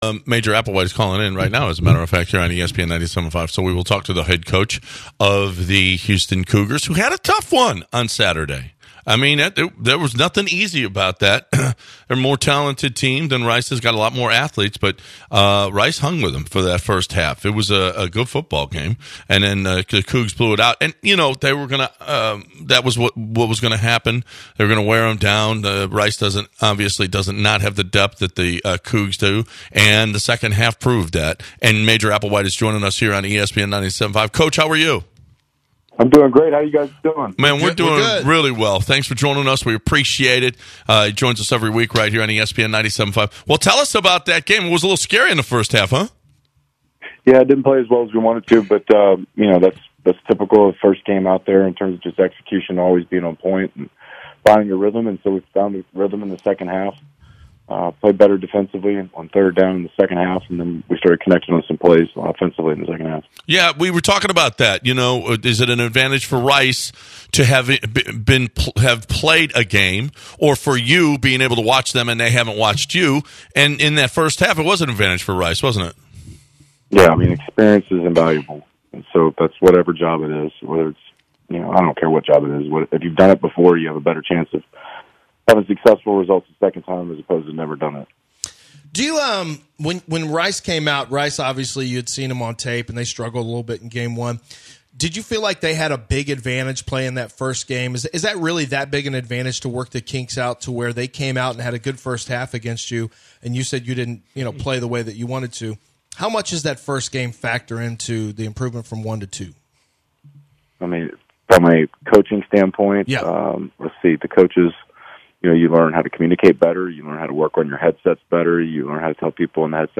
Interview
via phone